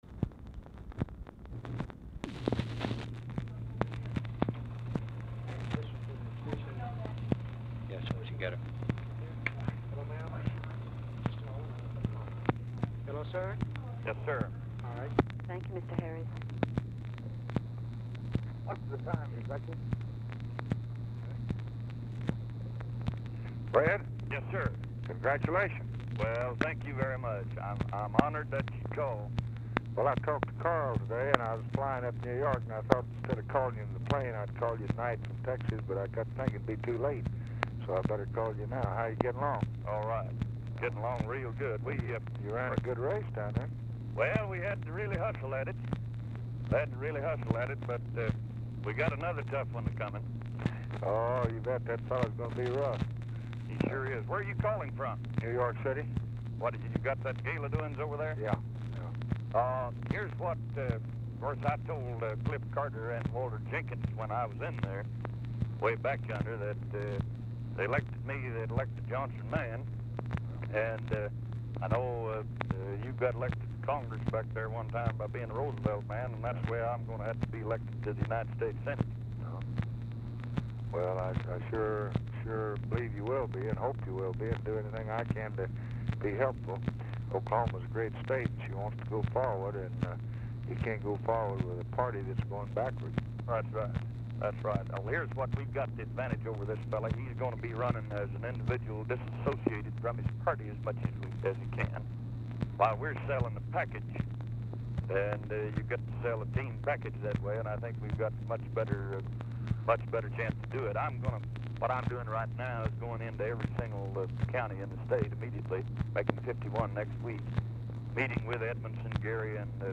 Telephone conversation # 3543, sound recording, LBJ and FRED HARRIS, 5/28/1964, 6:10PM
POOR SOUND QUALITY
Format Dictation belt